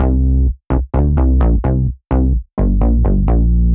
Index of /musicradar/french-house-chillout-samples/128bpm/Instruments
FHC_SulsaBass_128-C.wav